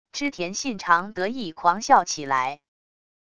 织田信长得意狂笑起来wav音频